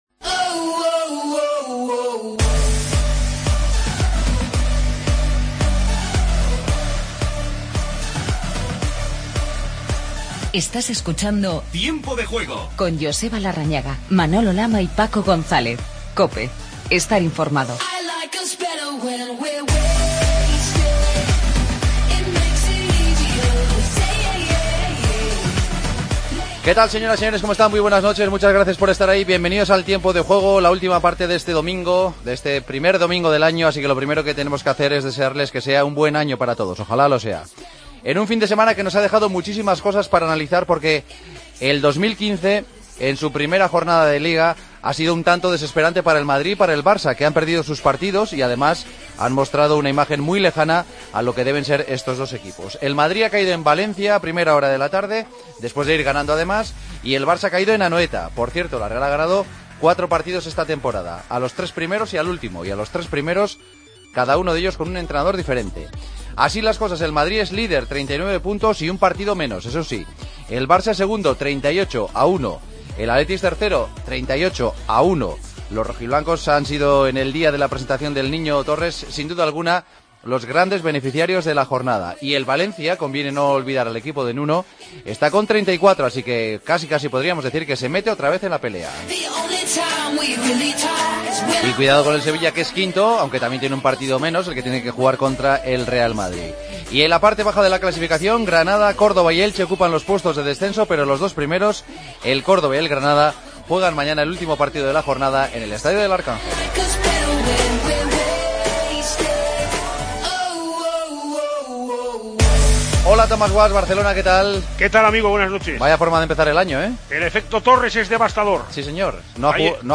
Real Madrid y Barcelona caen ante el Valencia y la Real Sociedad en la 17ª jornada de la Liga BBVA. Entrevista a Granero y escuchamos a Iniesta y Marcelo.